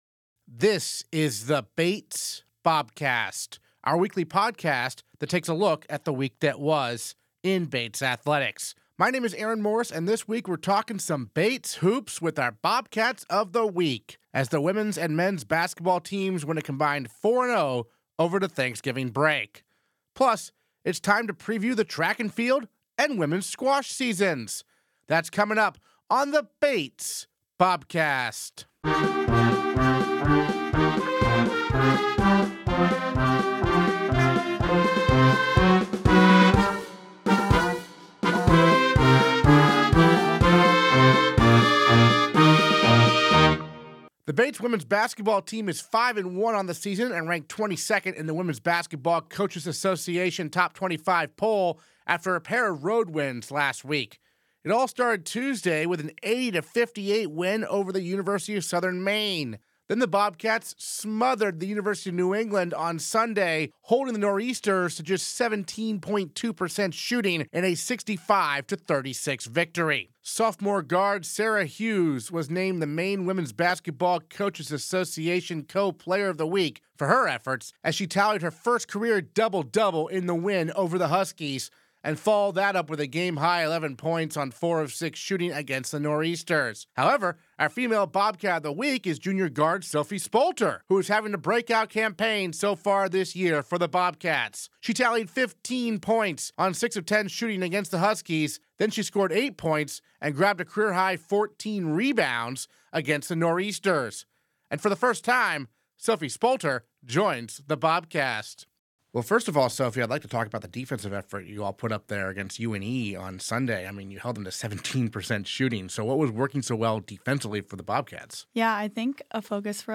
Interviews this episode: